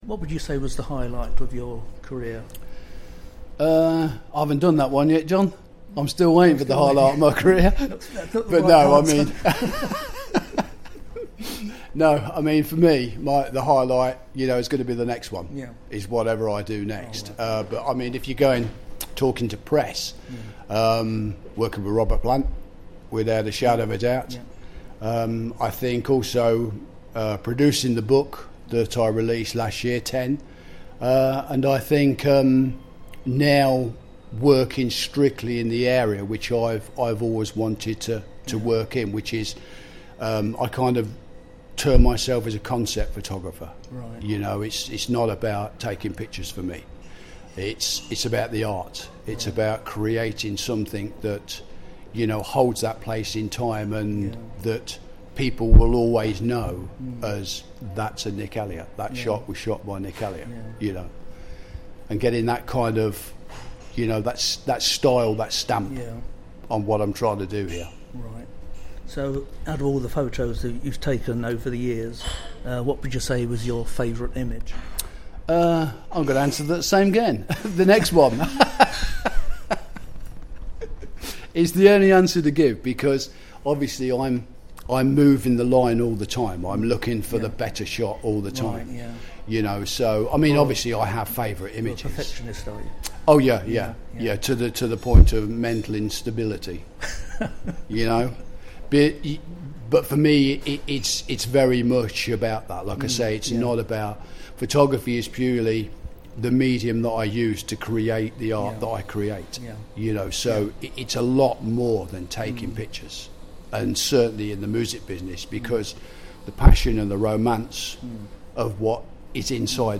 Interview
As you can hear, we had quite a giggle!